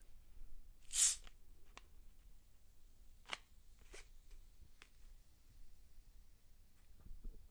描述：在一瓶苏打水上玩的节奏。
Tag: 无气 节奏 苏打